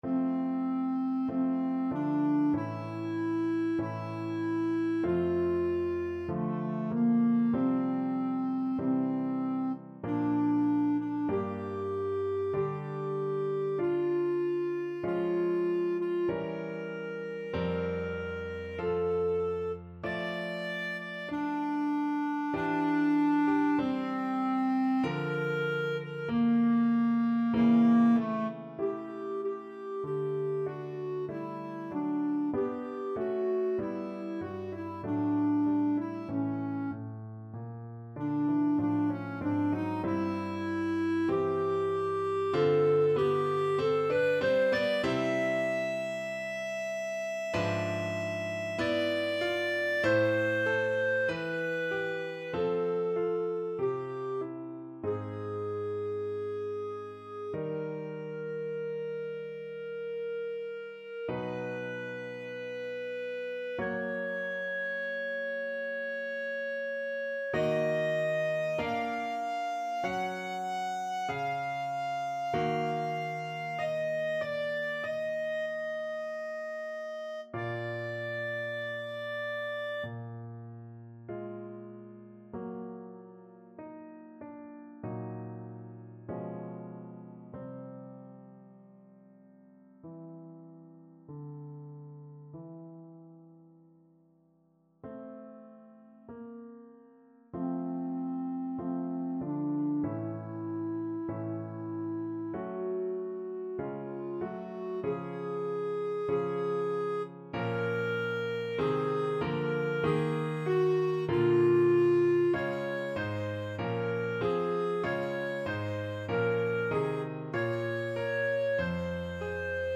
Classical Bruckner, Anton Ave Maria, WAB 7 Clarinet version
F major (Sounding Pitch) G major (Clarinet in Bb) (View more F major Music for Clarinet )
~ = 96 Alla breve. Weihevoll.
2/2 (View more 2/2 Music)
Classical (View more Classical Clarinet Music)